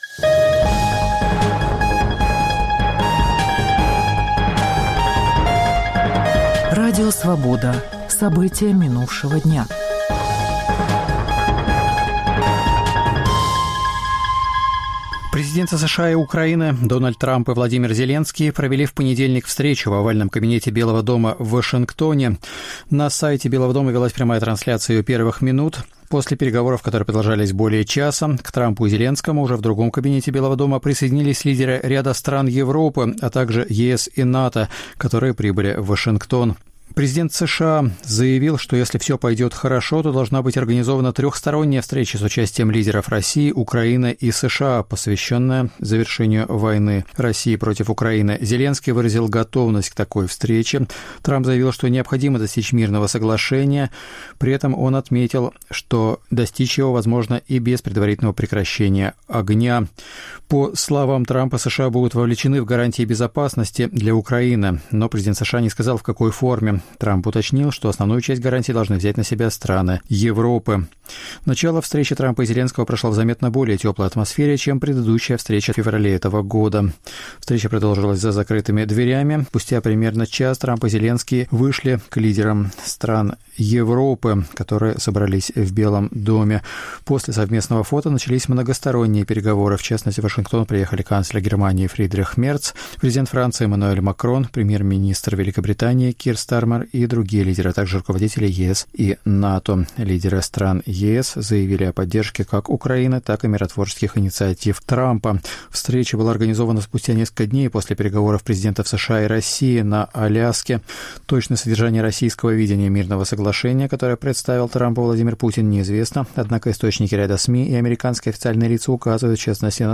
Новости Радио Свобода: итоговый выпуск